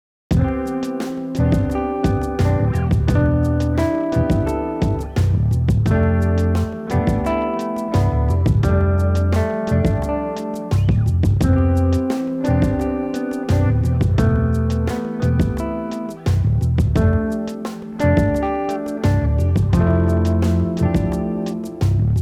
Suprano Sax and Tenor Sax
Guitar; virtual instruments; Drums, Bass, Keys